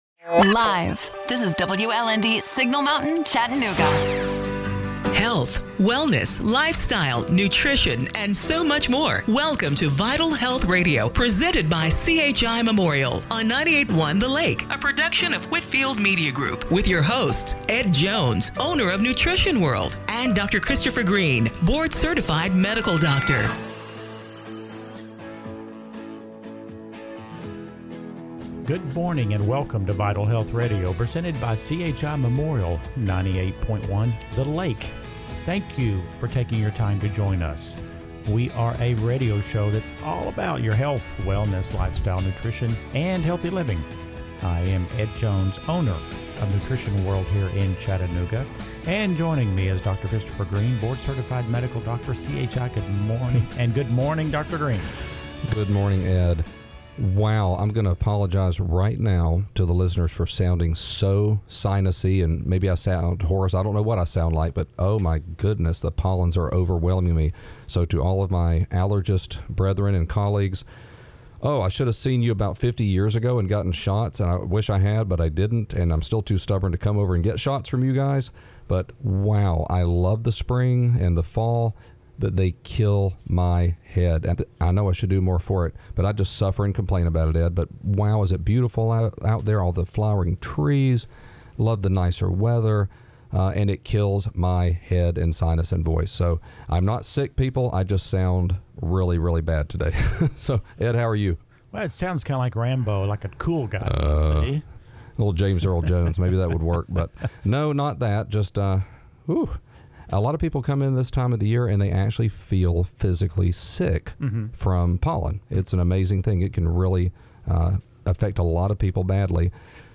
April 4, 2021 – Radio Show - Vital Health Radio